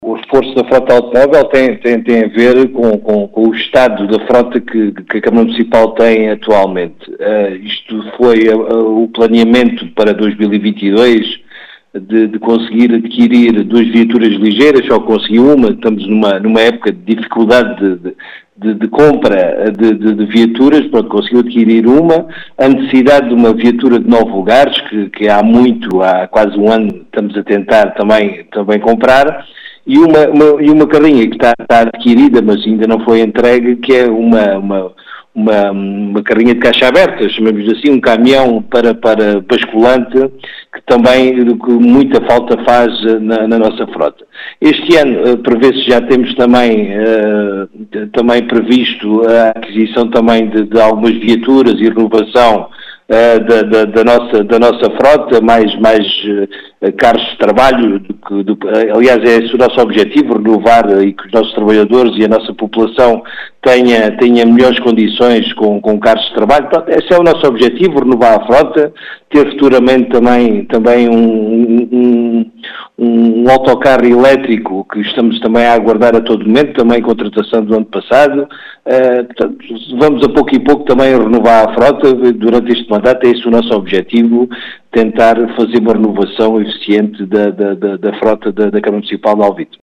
As explicações são de José Manuel Efigénio, presidente da Câmara Municipal de Alvito, que realça a importância deste “reforço da frota automóvel” do município.